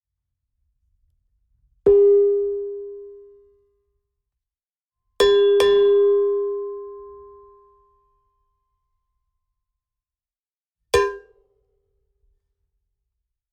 Звуки анимации
Эффект удара о препятствие